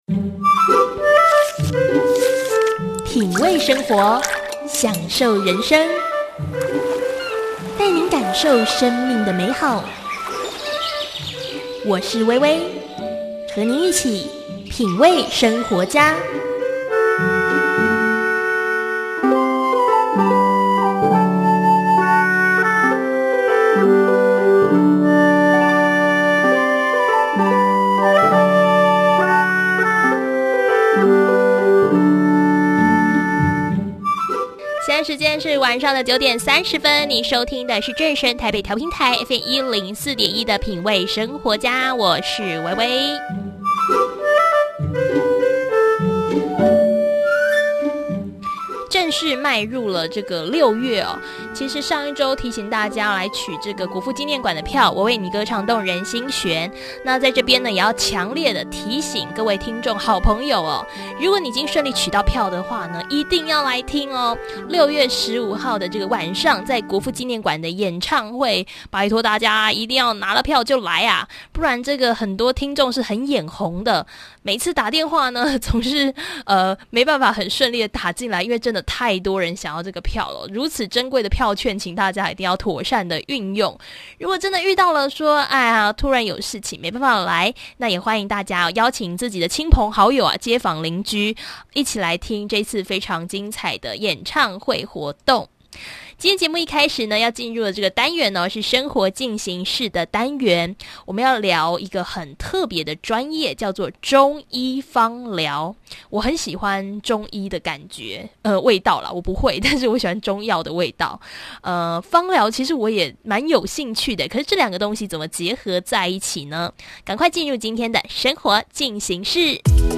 瞭解自己的體質，才能使用正確的精油，這次的訪談真的太精彩囉！